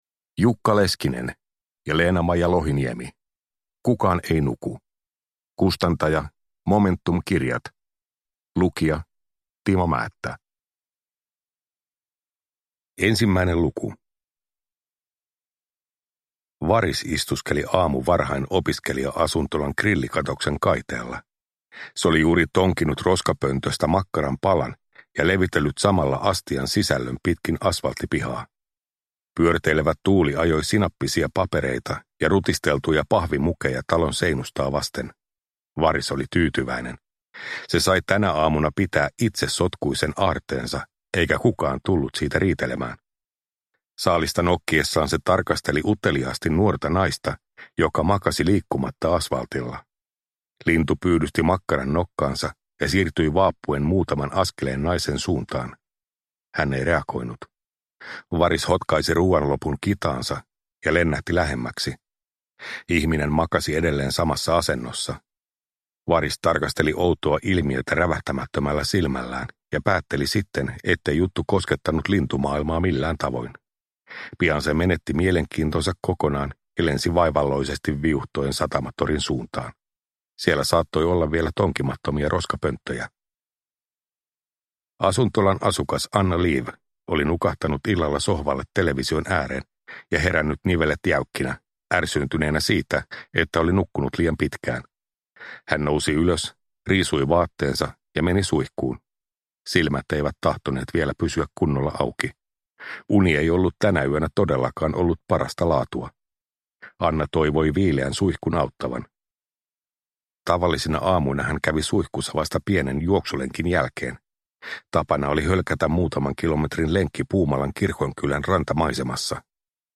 Kukaan ei nuku (ljudbok) av Jukka Leskinen